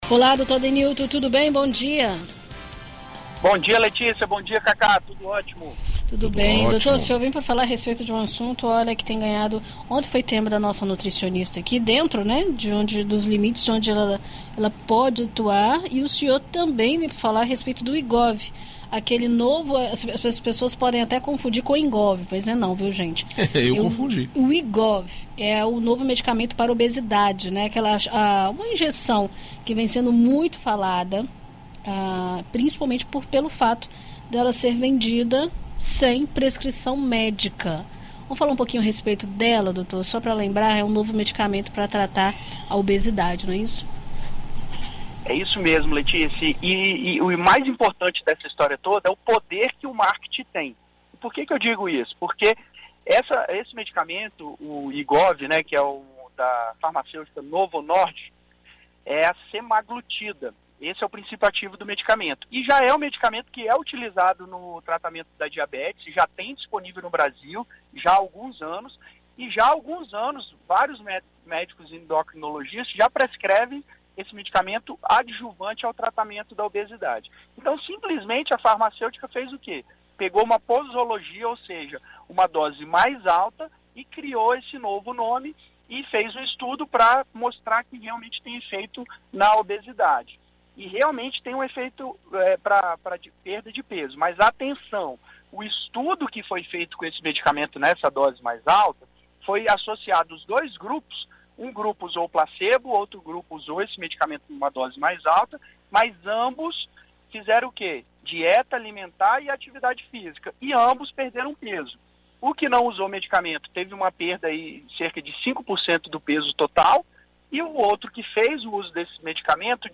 Na coluna “Visita Médica” desta quinta-feira (05), na BandNews FM ES